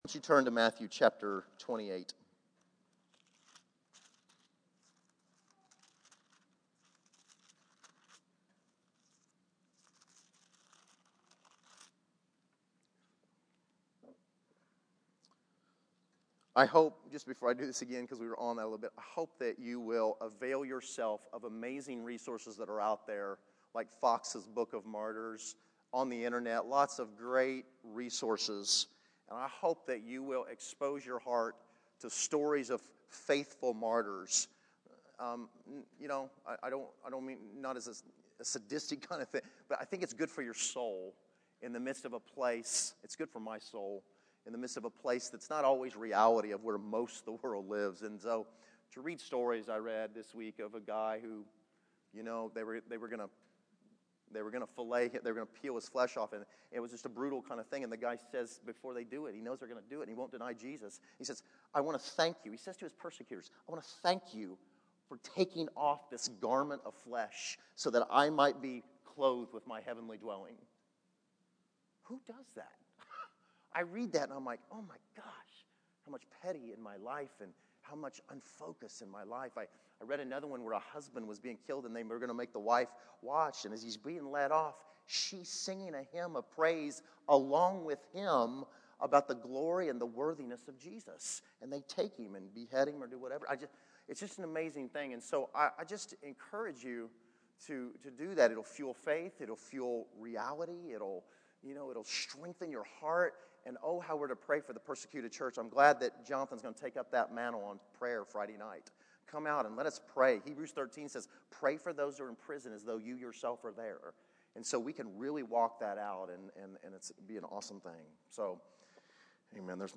To Obey March 01, 2015 Category: Sermons | Location: El Dorado Back to the Resource Library Video Audio The call to make disciples is centered around the call to teach people to obey.